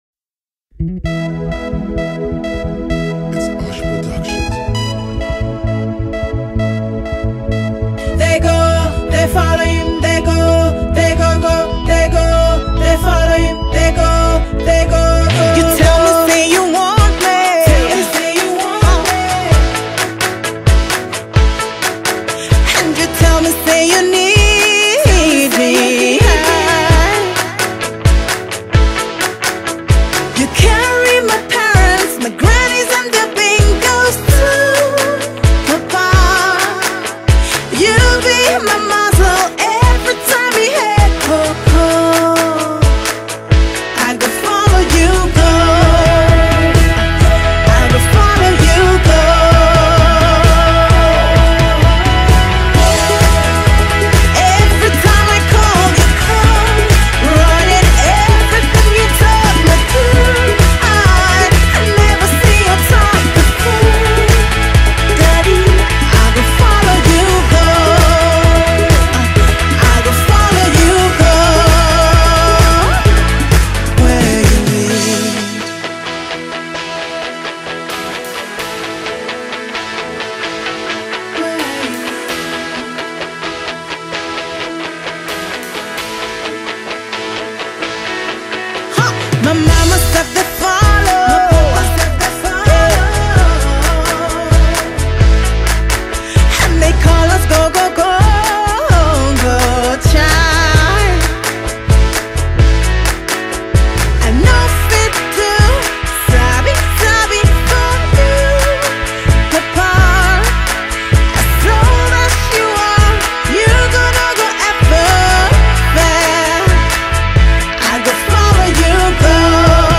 a soulful melody that lifts your spirit
Genre:Gospel